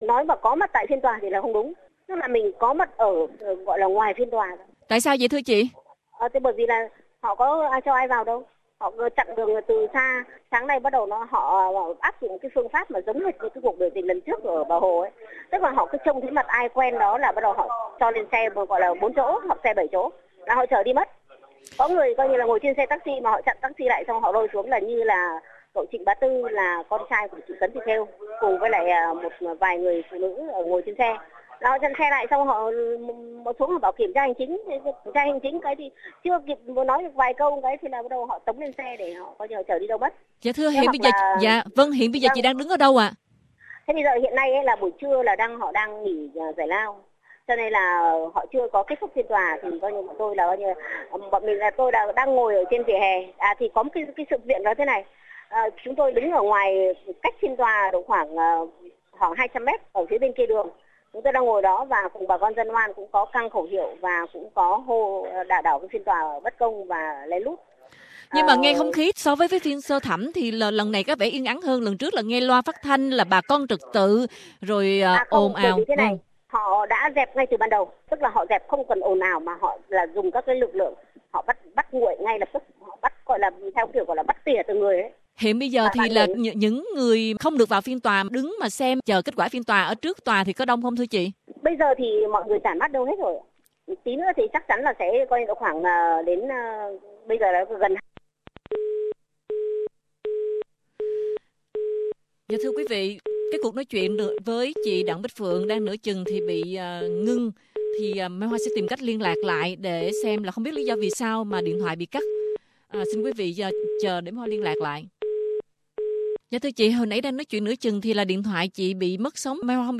Tường thuật từ bên ngoài phiên xử phúc thẩm